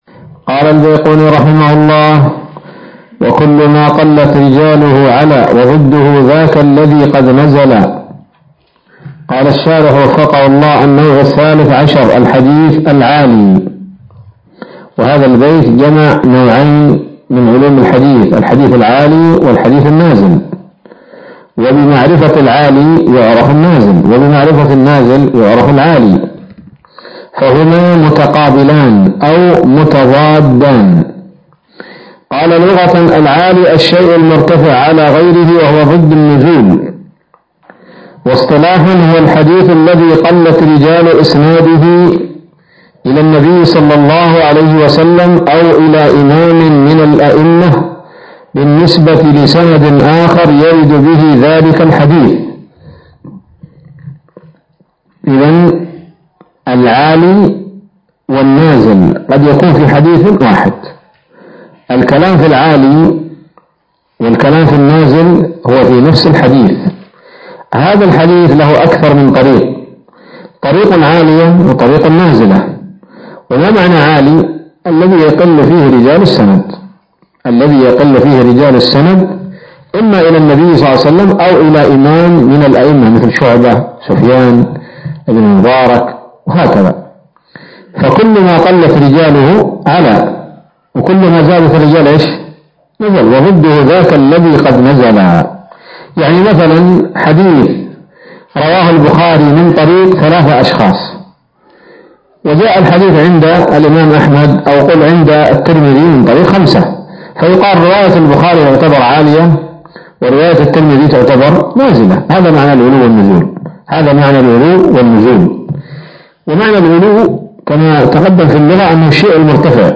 الدرس العشرون من الفتوحات القيومية في شرح البيقونية [1444هـ]